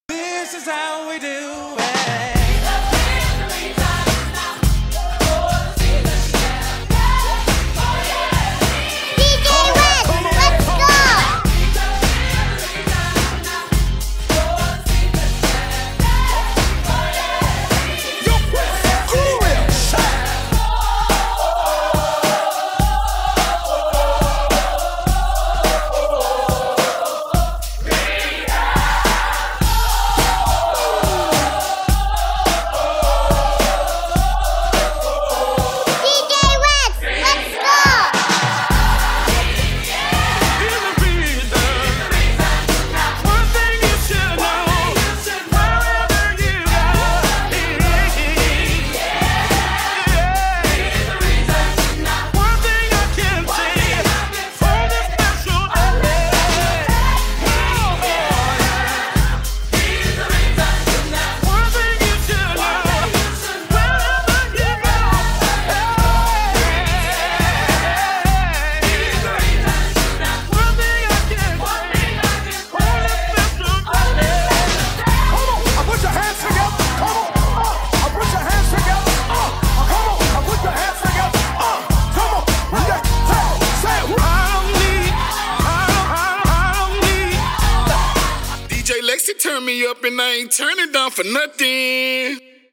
Gospel
HipHop